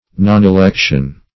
nonelection - definition of nonelection - synonyms, pronunciation, spelling from Free Dictionary
Nonelection \Non`e*lec"tion\, n. Failure of election.